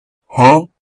Huh